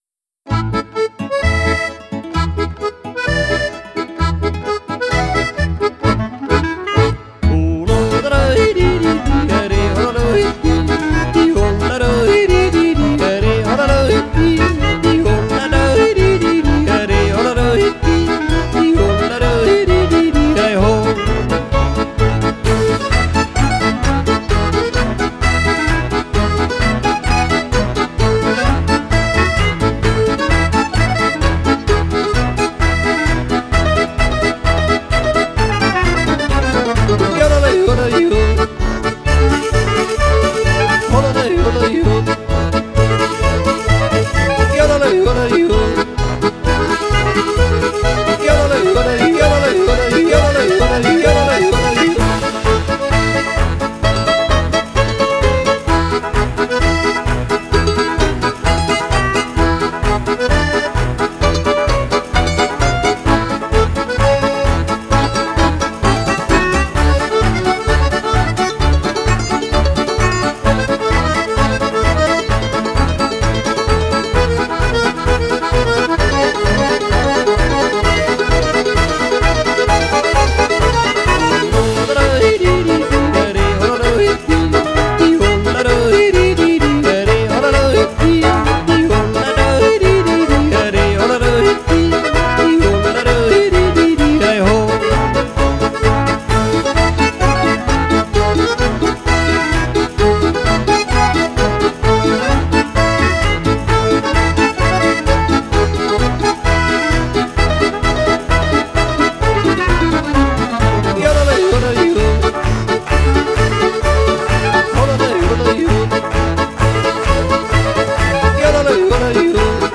polka music with plenty of yodeling.